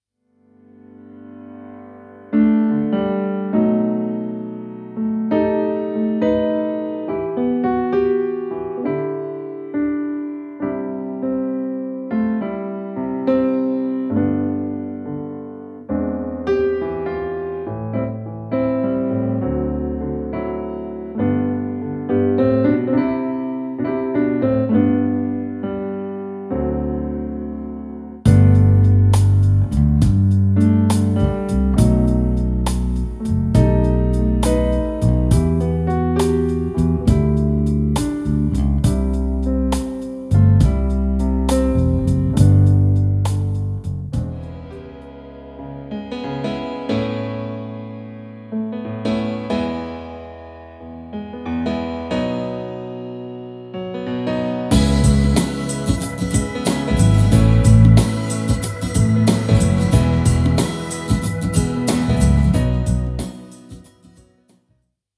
Background Music Samples.